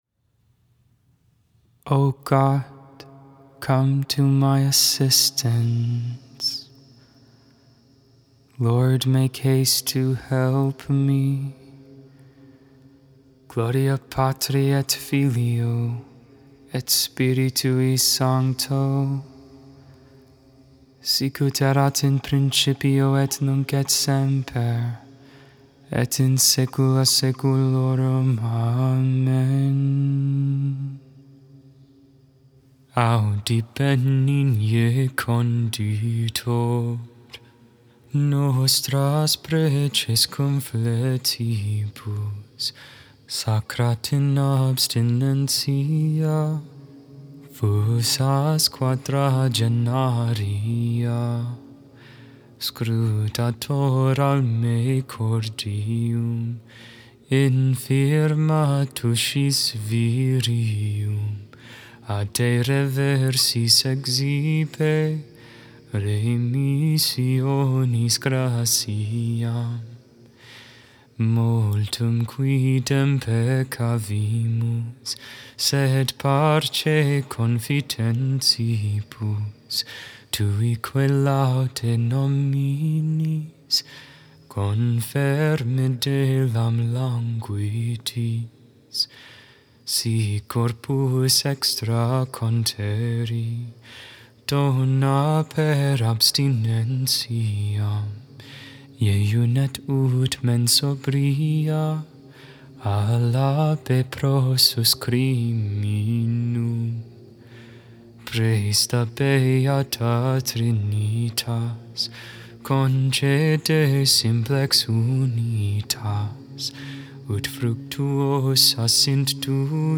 Vespers